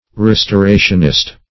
Search Result for " restorationist" : The Collaborative International Dictionary of English v.0.48: Restorationist \Res`to*ra"tion*ist\, n. One who believes in a temporary future punishment and a final restoration of all to the favor and presence of God; a Universalist.